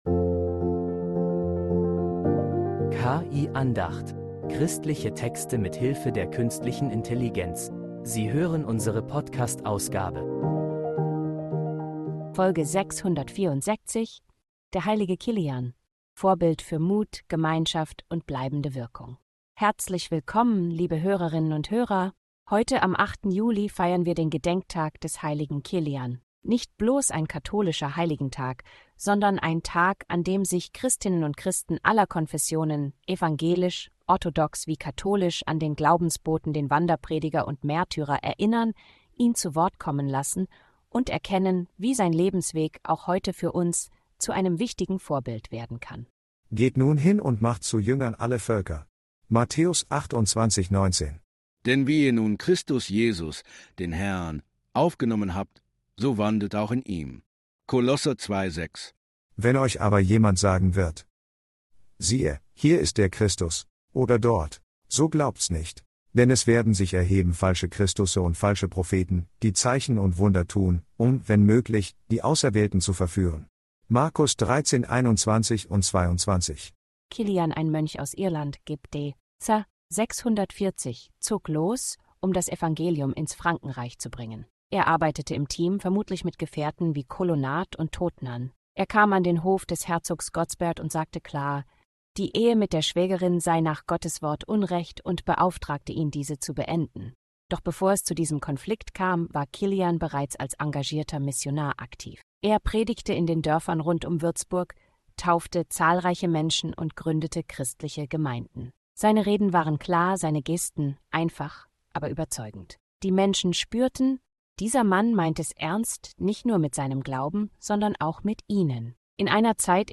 Eine Andacht, die Mut macht, die